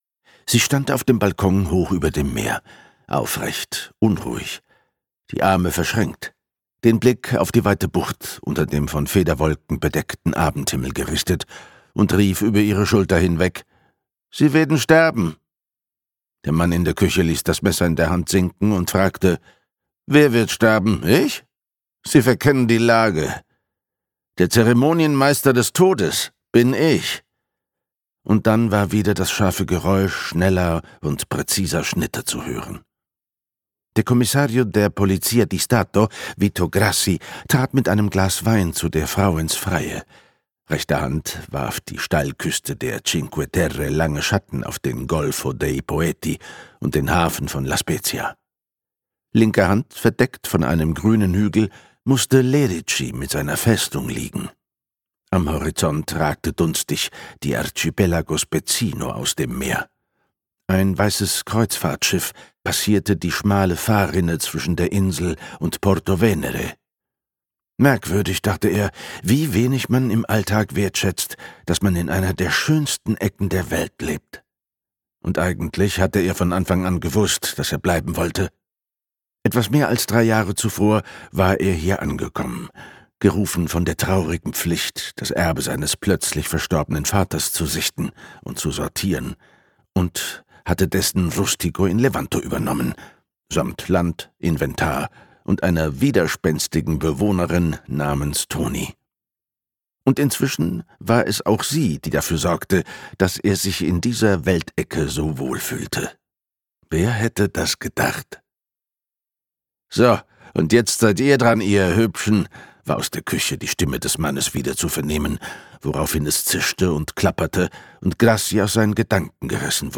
Ein romantischer Tod - Andrea Bonetto | argon hörbuch
Gekürzt Autorisierte, d.h. von Autor:innen und / oder Verlagen freigegebene, bearbeitete Fassung.